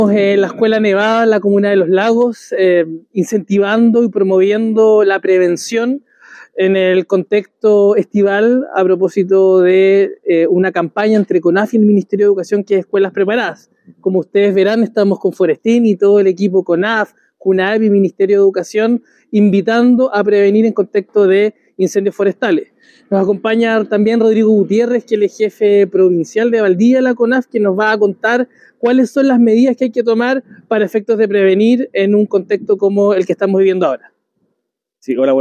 El Seremi de Educación Juan Pablo Gerter Urrutia se refirió a la campaña de prevención en período estival.